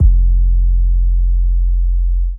SouthsideTrapBack 808.wav